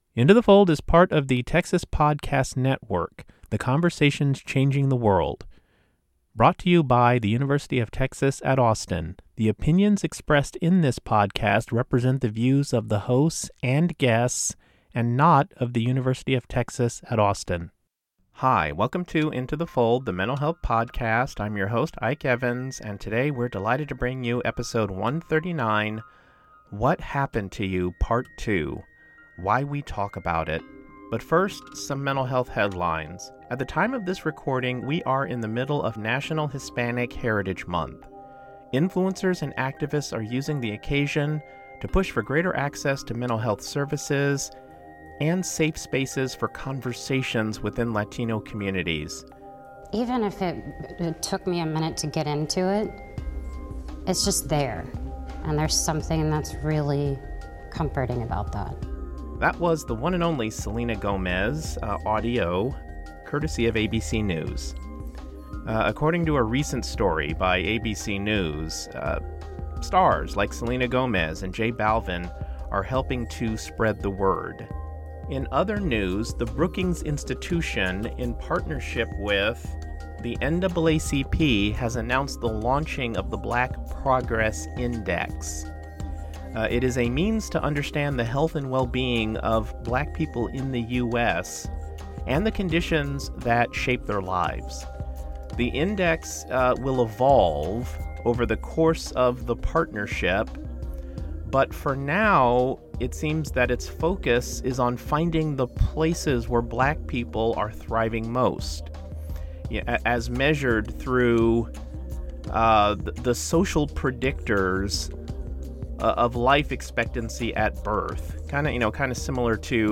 speaks with Dr. Bruce Perry, co-author with Oprah Winfrey of the recent best-selling book, What Happened to You?: Conversations on Trauma, Resilience, and Healing, about the increased openness and evolving approach to understanding trauma.